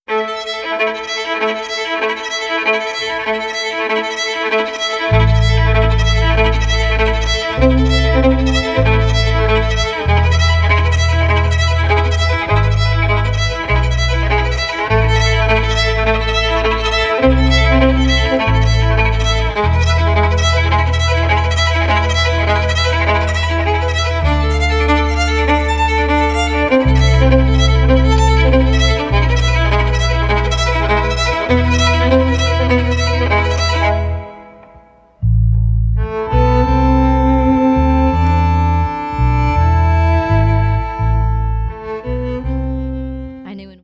(dramatic, staccato violin with bass)